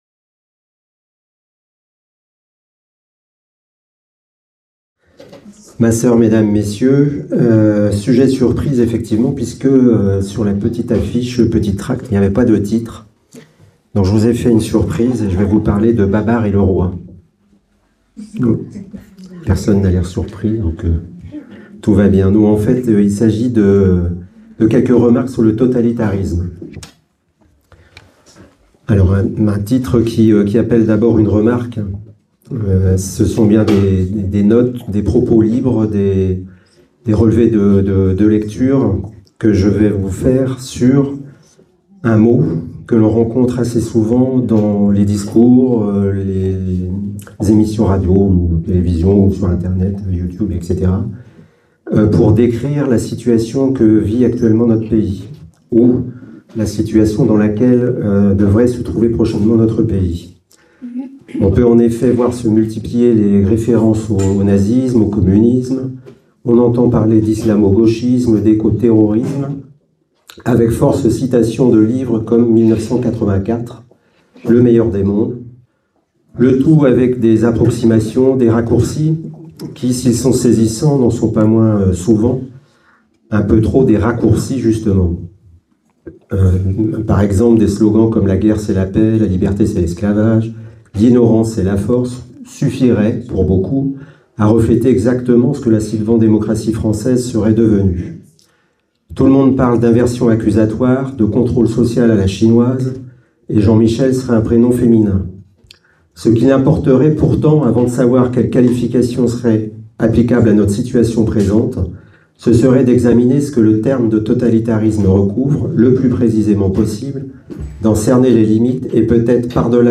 Conference-UCLF.mp3